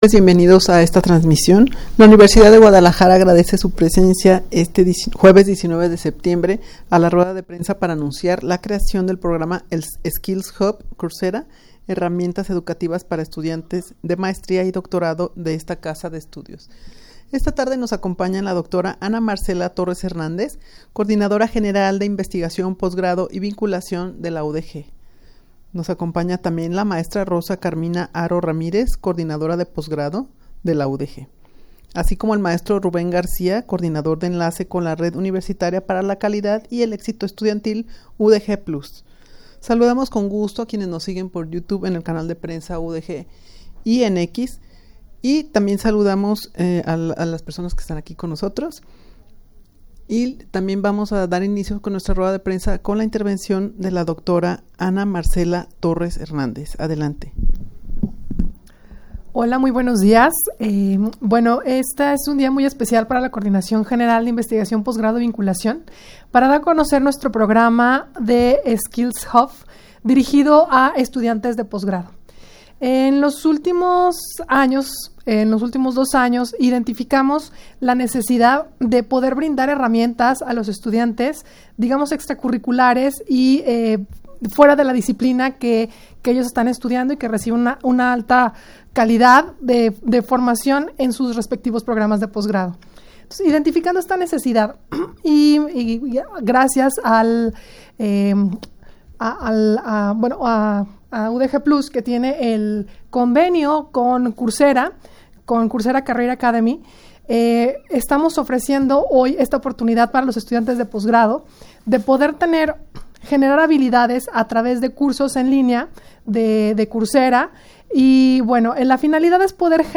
Audio de la Rueda de Prensa
rueda-de-prensa-para-anunciar-la-creacion-del-programa-skills-hub-coursera.mp3